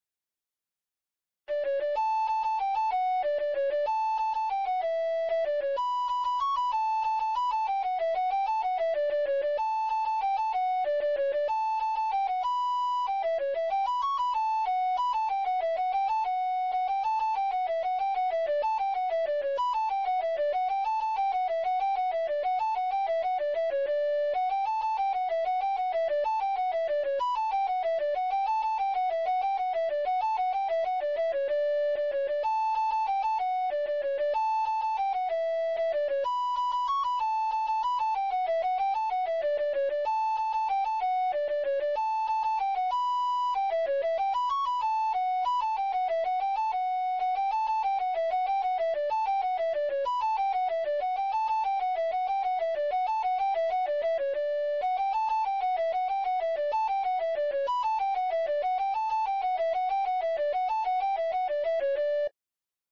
Primeira voz